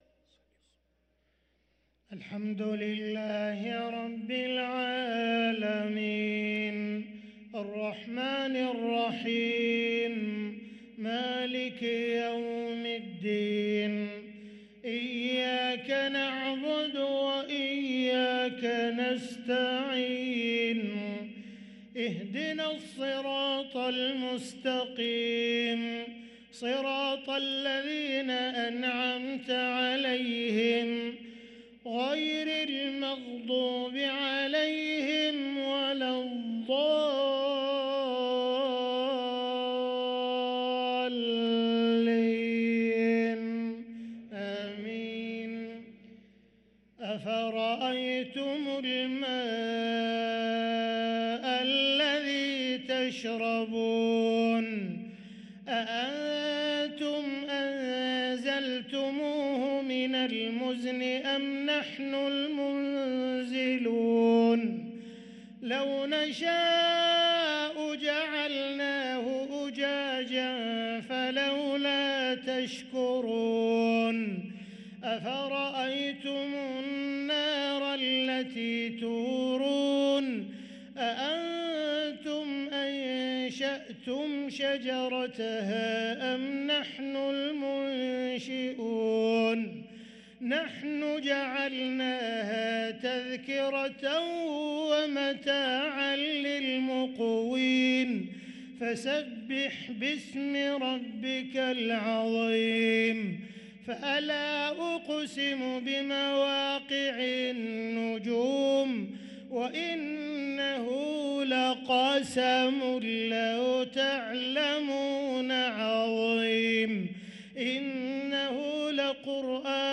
صلاة العشاء للقارئ عبدالرحمن السديس 19 جمادي الآخر 1444 هـ